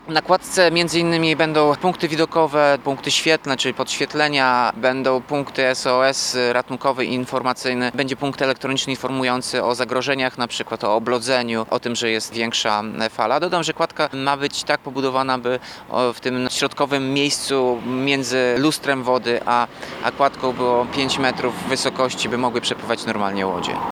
– Będzie to bardzo nowoczesna budowla – mówi Tomasz Andrukiewicz, prezydent Ełku.